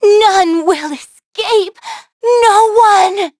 Xerah-Vox_Dead.wav